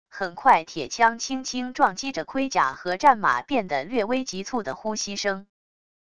很快铁枪轻轻撞击着盔甲和战马变得略微急促的呼吸声wav音频